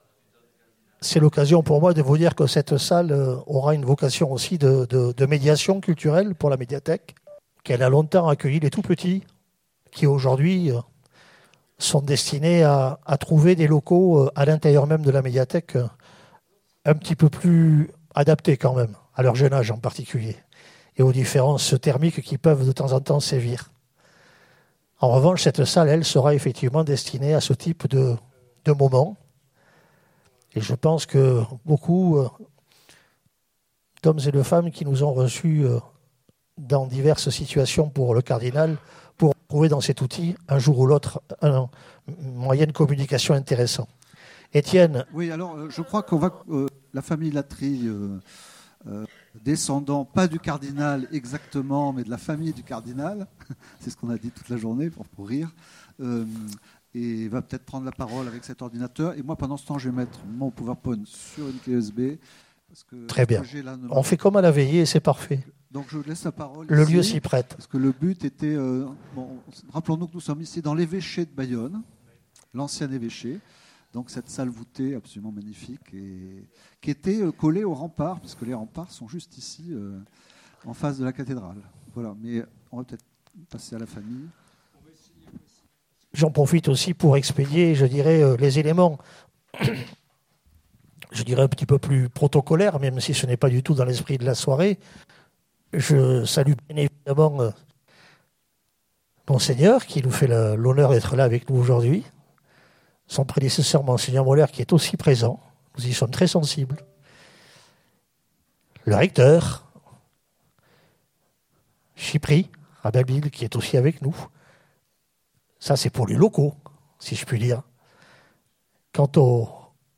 Enregistrement réalisé le 30 octobre 2025 à la médiathèque de Bayonne.